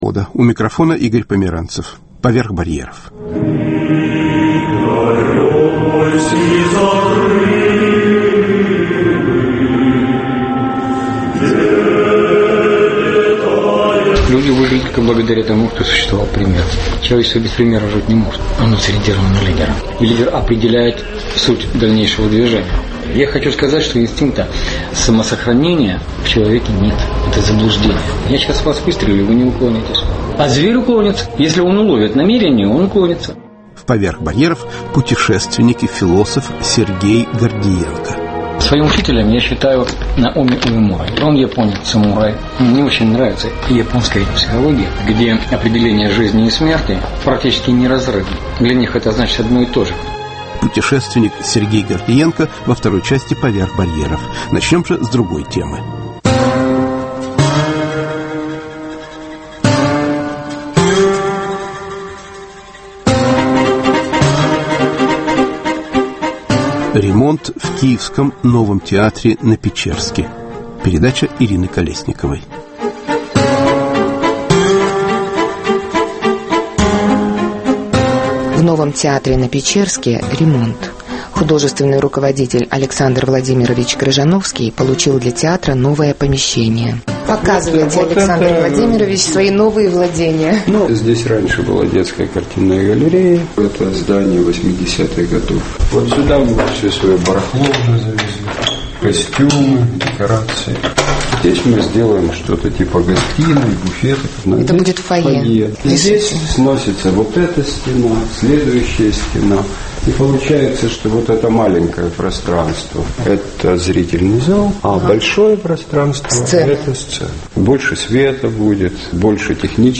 Разговор с театральными художниками, режиссёром и актёрами о том, какое отношение к искусству имеет интерьер театра.